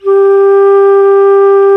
Index of /90_sSampleCDs/Roland LCDP04 Orchestral Winds/FLT_Alto Flute/FLT_A.Flt nv 3
FLT ALTO F08.wav